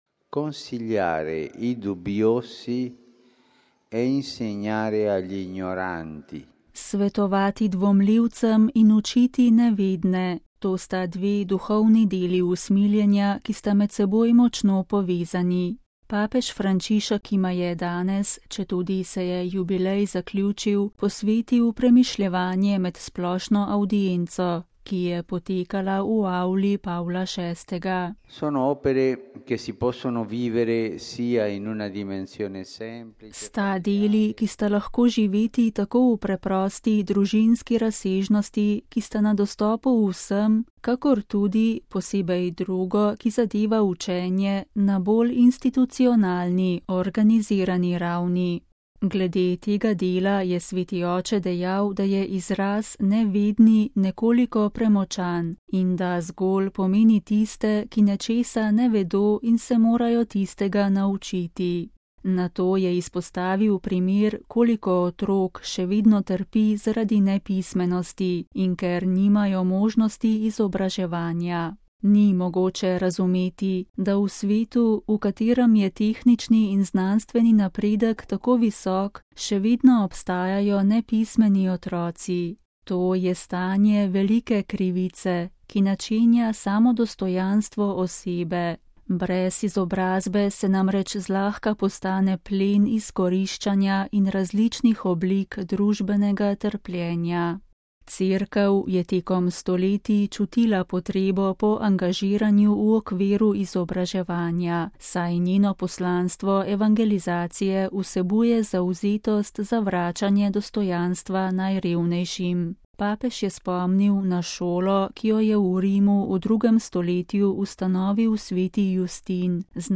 VATIKAN (sreda, 23. november 2016, RV) – Svetovati dvomljivcem in učiti nevedne: to sta dve duhovni deli usmiljenja, ki sta med seboj močno povezani. Papež Frančišek jima je danes, četudi se je jubilej zaključil, posvetil premišljevanje med splošno avdienco, ki je potekala v avli Pavla VI.